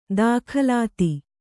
♪ dākhalāti